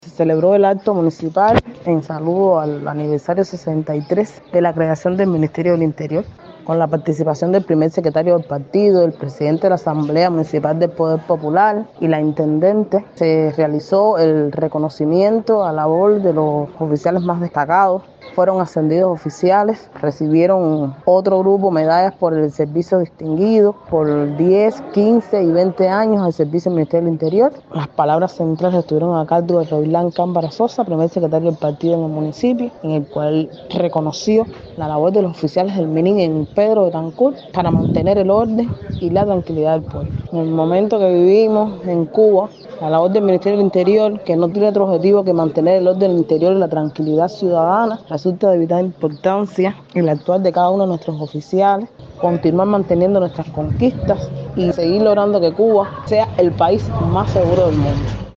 Así lo expresó a nuestra emisora Marina Yadelsi Morejón Aldama, diputada a la Asamblea Nacional del Poder Popular y oficial de coordinación y apoyo del jefe del Minint municipal.